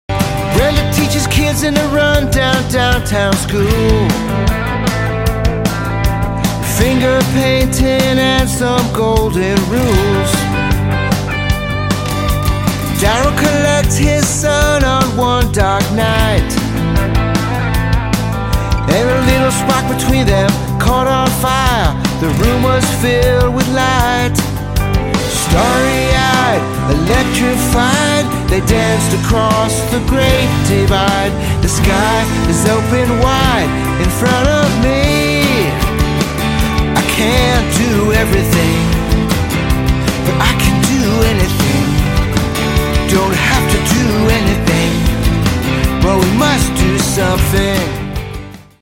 🇺🇸 Make sure you're registered to vote! I created this original song because it's time for us to stand up and rise together!